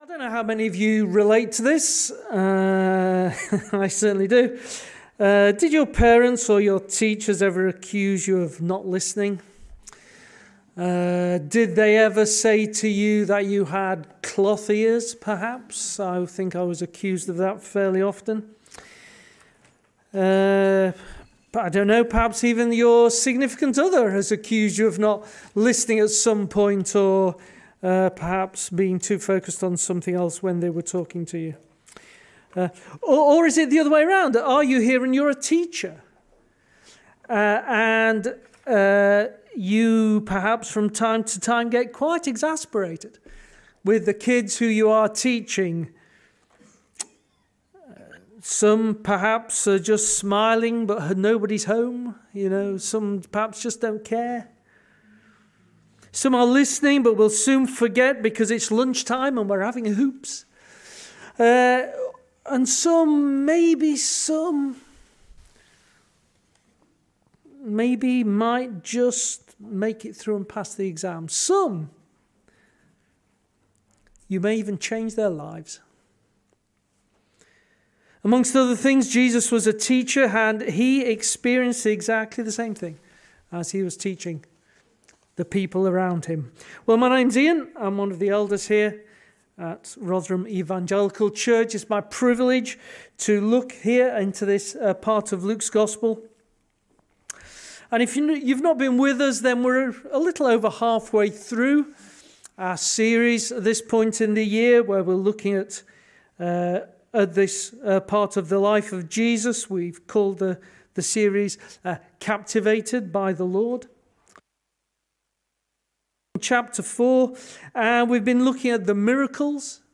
Weekly sermons from Rotherham Evangelical Church, South Yorkshire, UK.